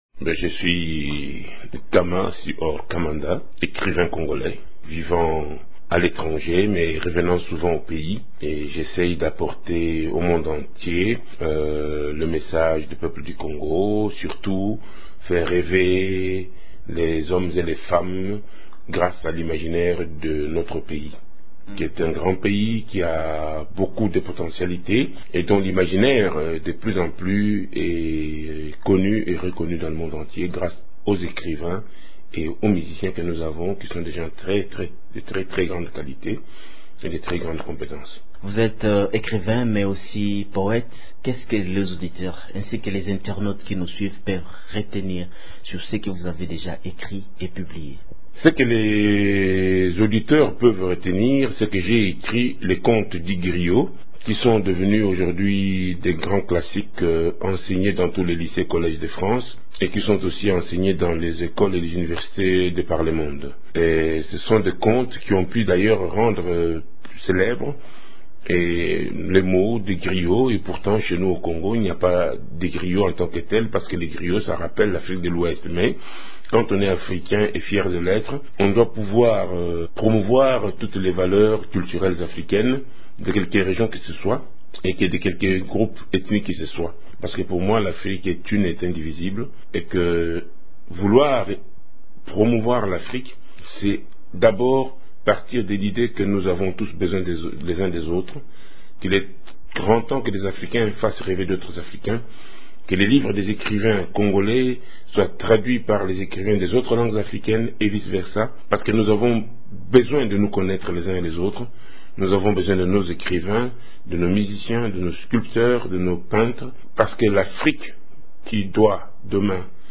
l’artiste parle de ses œuvres et de son Congo natal.